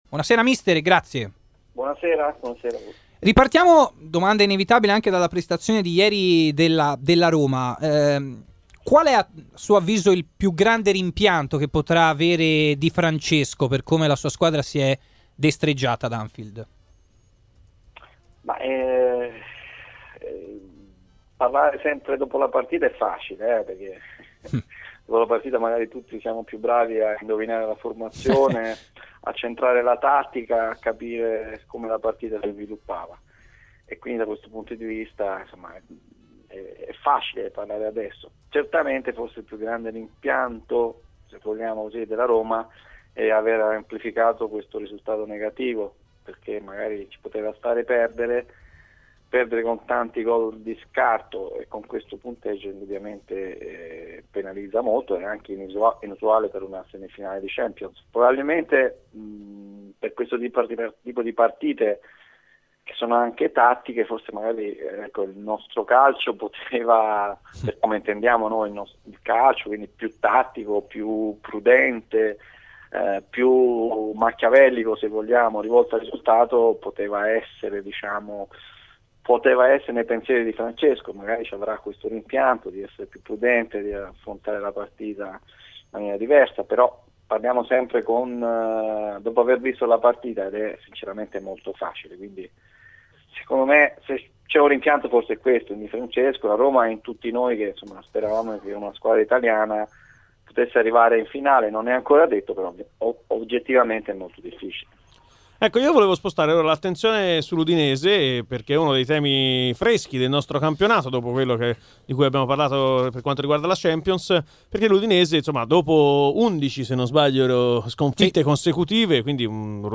intervenuto nel corso del Live Show su RMC Sport ha commentato così la sconfitta di ieri della Roma con il Liverpool